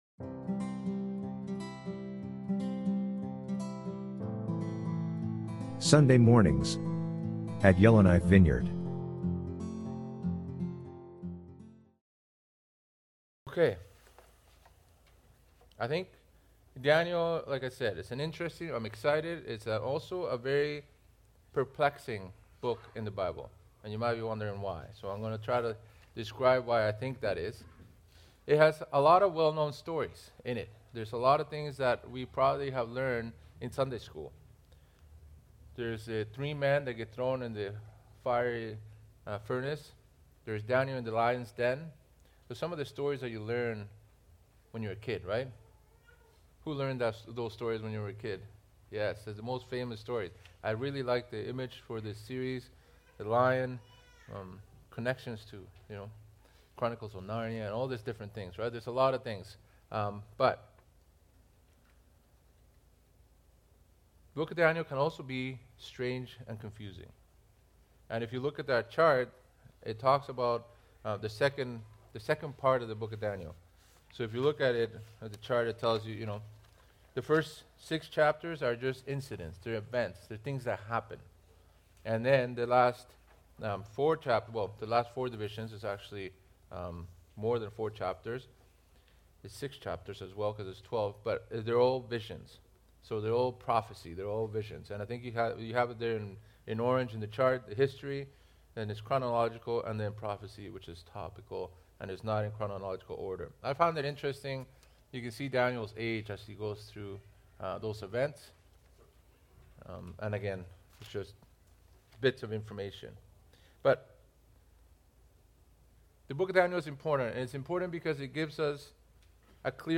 June 22, 2025 - Sunday Sermon